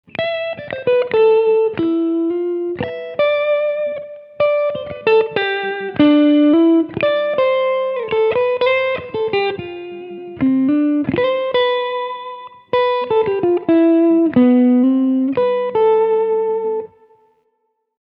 Ich habe es mal eben mit der Gitarre eingespielt, damit Ihr wisst was ich meine, liegt hier: